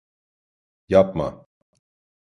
Pronunciado como (IPA)
/jɑpˈmɑ/